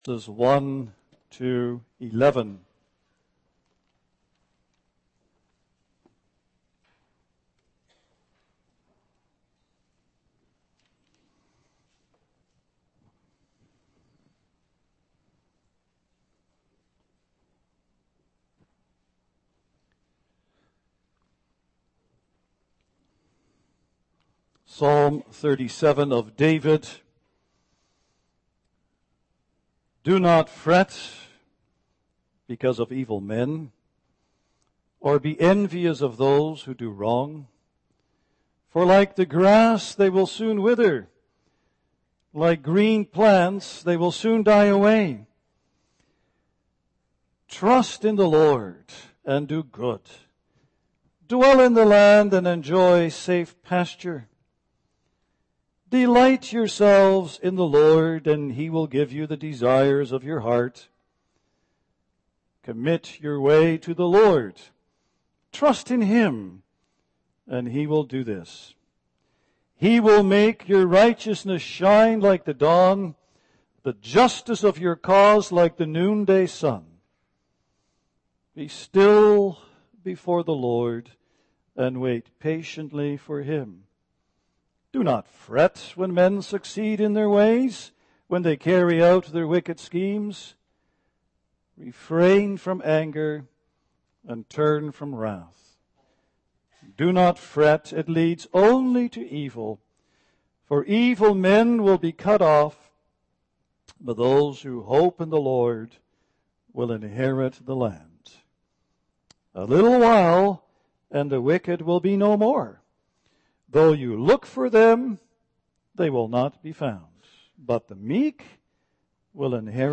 Service Type: Sunday Afternoon